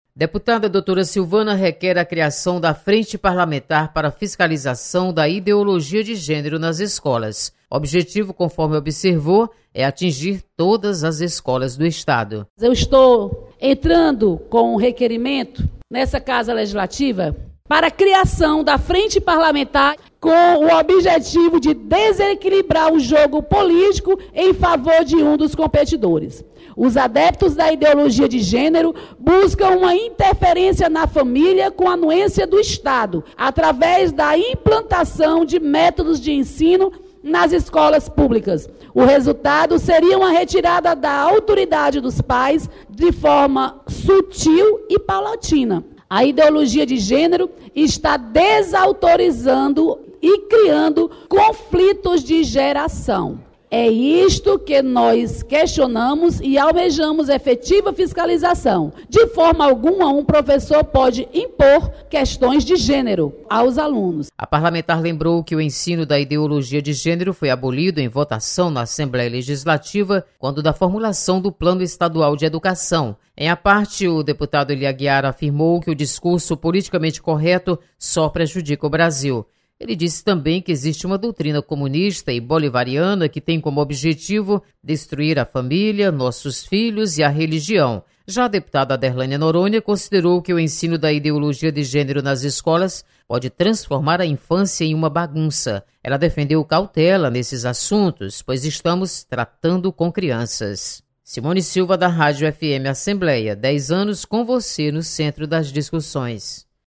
• Fonte: Agência de Notícias da Assembleia Legislativa